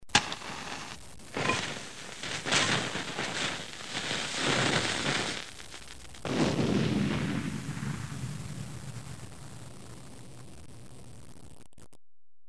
دانلود صدای بمب و موشک 13 از ساعد نیوز با لینک مستقیم و کیفیت بالا
جلوه های صوتی